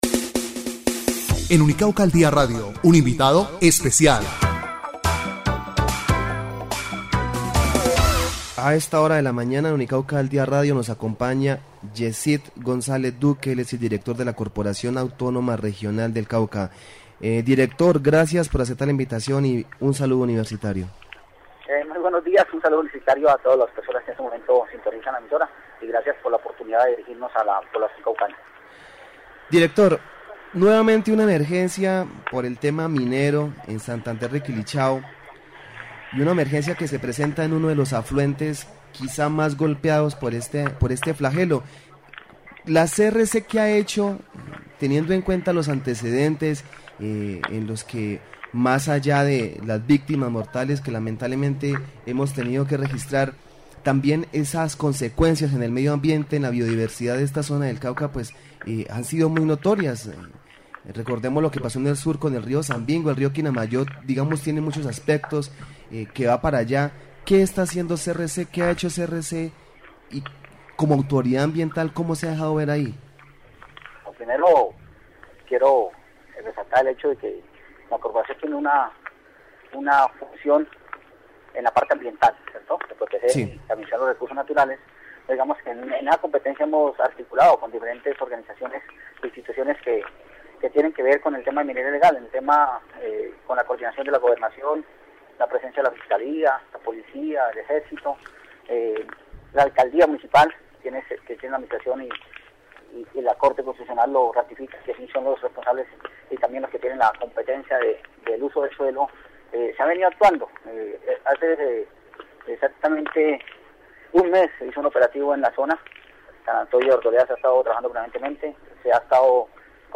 Entrevista Yesid González Duque - Director de la Corporación Autonoma Regional del Cauca